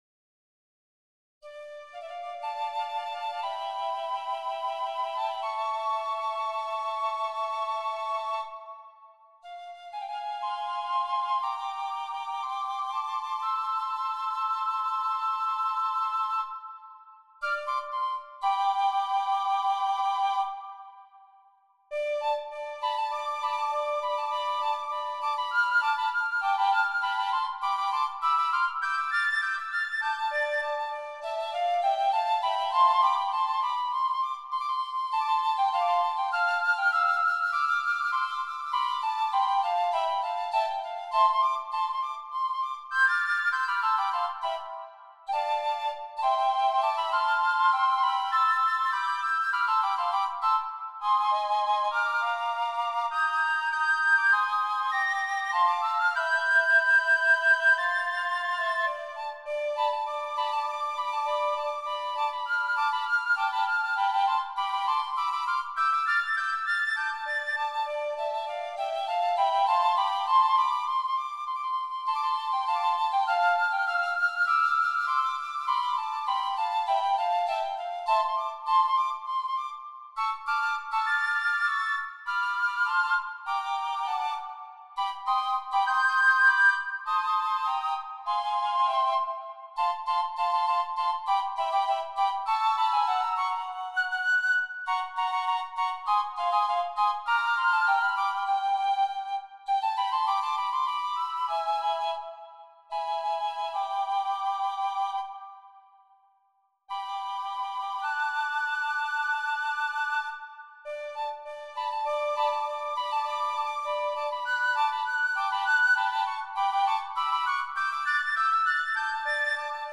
After a simple introduction, an allegretto canon at the quarter note interval trips along, at first in D Dorian , Moving through harmonic domains quickly, it returns tinged with major, before ceding to a short andante clearly in C.
The more lyrical andante is a simple melodic line with accompaniment.
Thereafter a moment in 5/8 erases the overt major of the preceding andante.
An allegro and then final allegretto reprising the first section's gestures finish this small set of "morsels."
8 pages, circa 7' 00" - an MP3 demo is here:
Tidbits for Three Piccolos.mp3